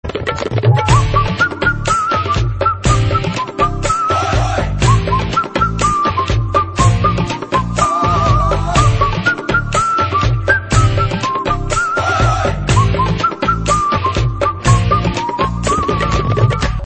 • Classical Ringtones